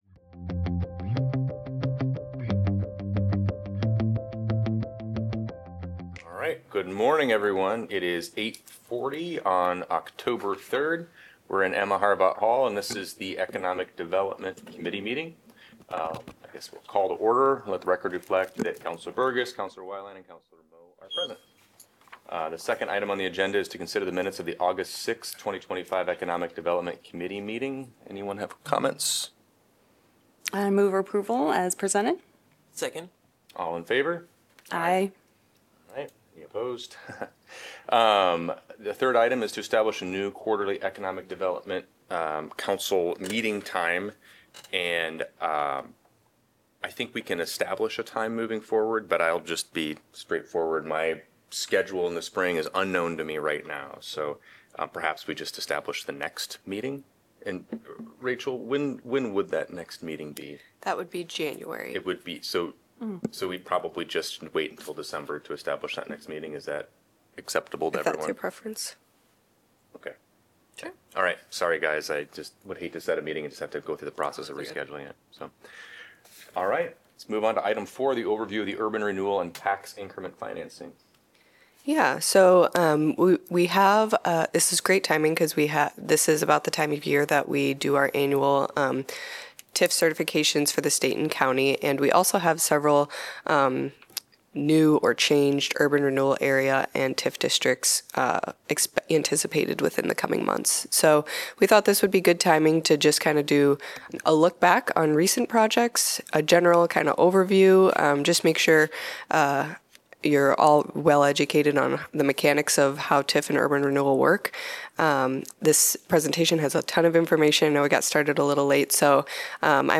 A meeting of the Iowa City City Council's Economic Development Committee.